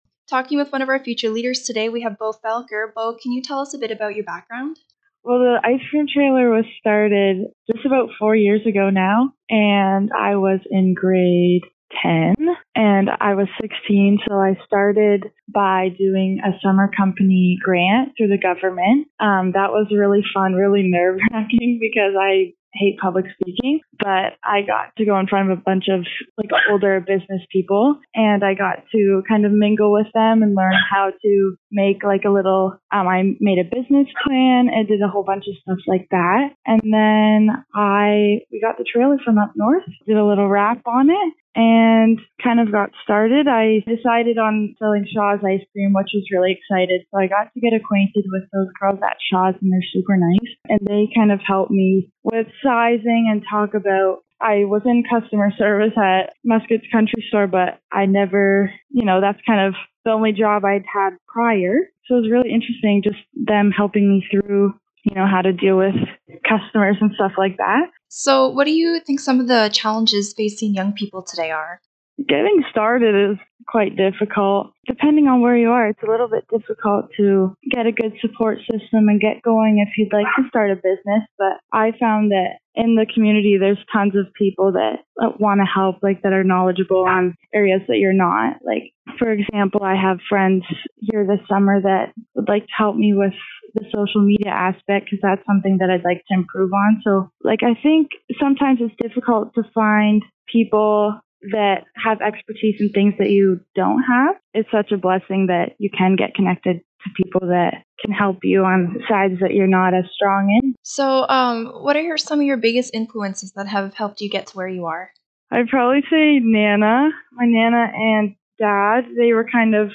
Here is the complete interview with this future leader